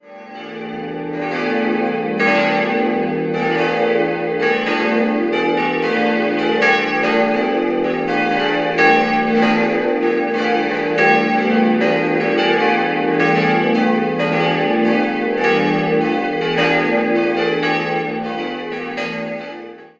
Von dieser Ausstattung sind heute nur noch Altar und Kanzel erhalten, die übrigen Einrichtungsgegenstände sind neubarock. 4-stimmiges Geläute: d'-f'-g'-as' Die Glocken wurden 1921 vom Bochumer Verein für Gussstahlfabrikation gegossen.